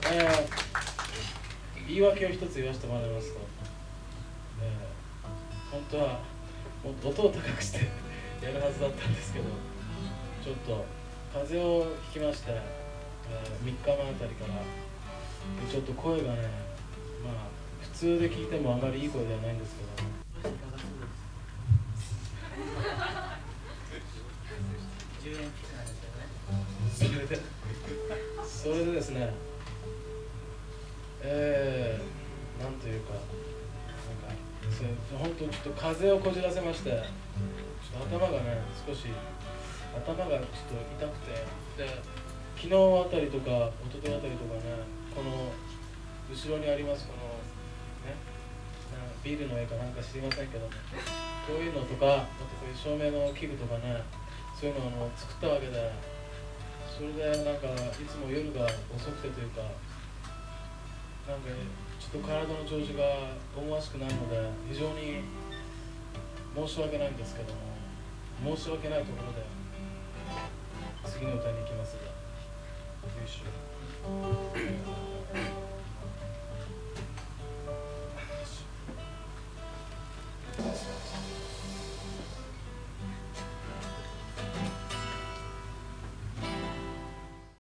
1980年武蔵新城「珈琲専科　亜豆」LIVE